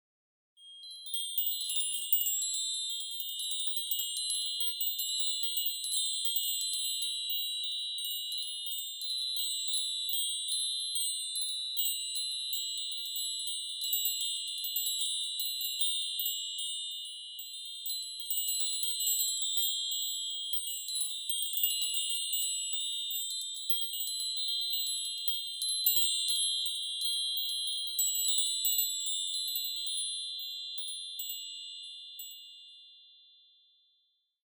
Meinl Sonic Energy Mini Hamsa Chakra Chime 12"/30 cm - Silver (HCC12MINI)